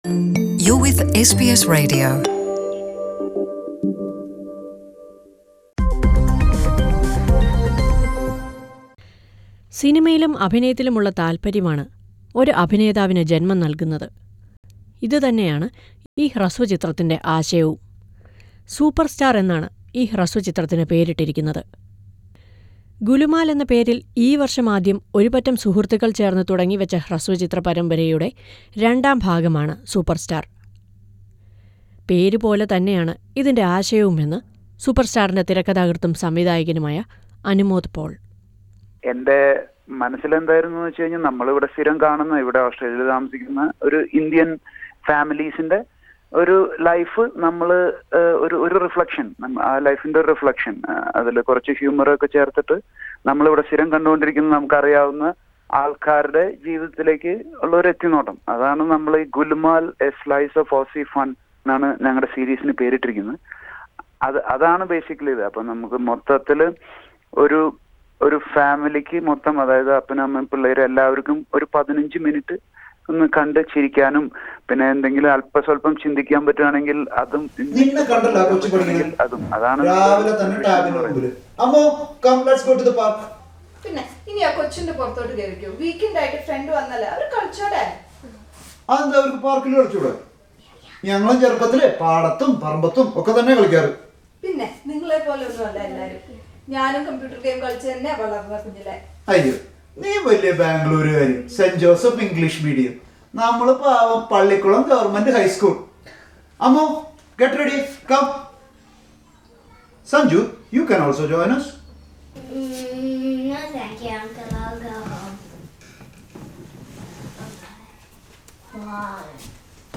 Listen to a report on a Malayalam short film series being released in Australia.